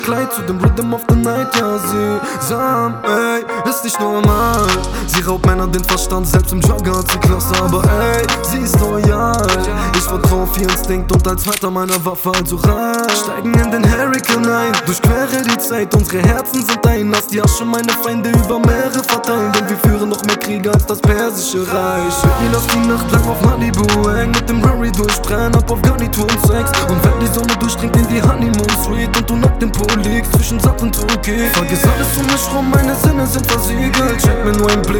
# Рэп